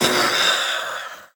Minecraft Version Minecraft Version latest Latest Release | Latest Snapshot latest / assets / minecraft / sounds / mob / phantom / death2.ogg Compare With Compare With Latest Release | Latest Snapshot